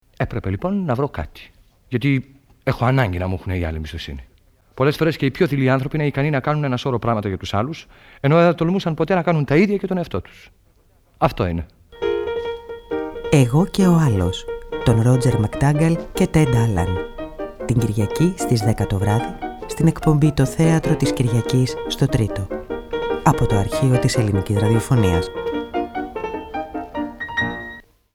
Από το Αρχείο της Ελληνικής Ραδιοφωνίας
Η πρώτη ραδιοφωνική μετάδοση του έργου πραγματοποιήθηκε στην εκπομπή «Το Θέατρο της Κυριακής» από το Ε.Ι.Ρ, τον Σεπτέμβριο του 1960, με τον Δημήτρη Χορν στον πρωταγωνιστικό ρόλο.